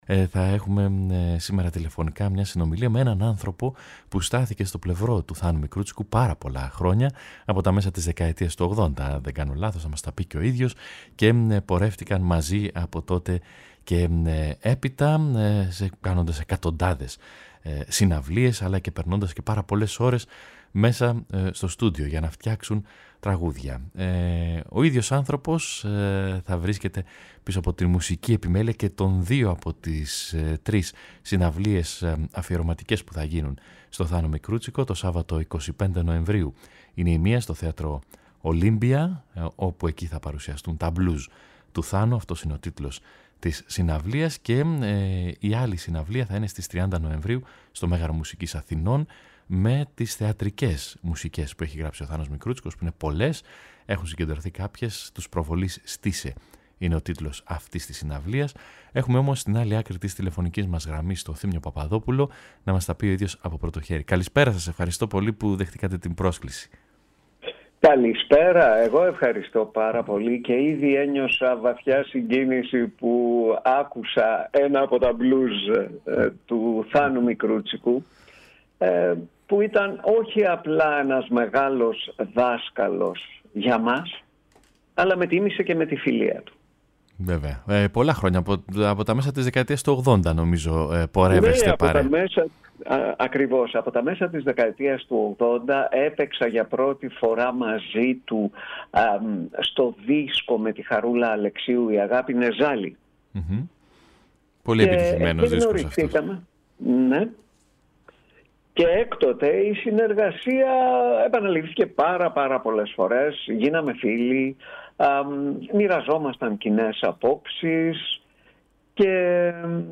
συνομιλεί τηλεφωνικά
Συνεντεύξεις